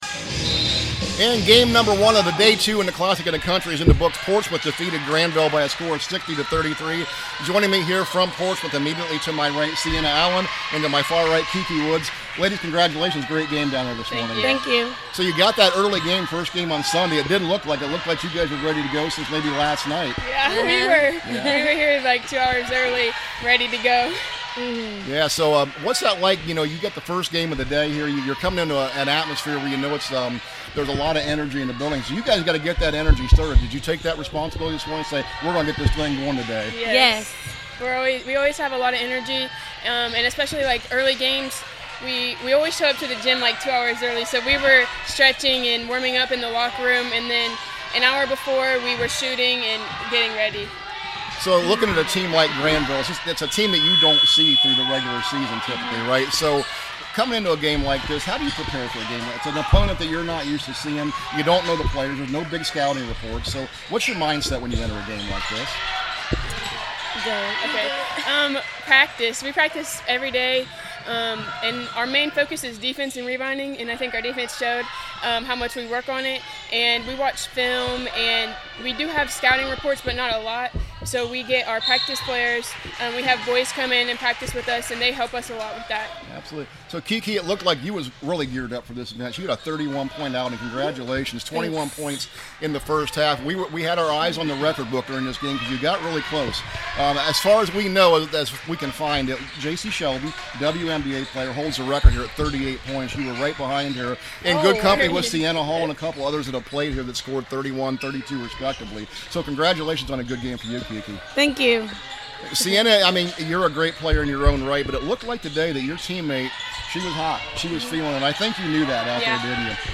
CLASSIC 2026 – PORTSMOUTH PLAYERS INTERVIEW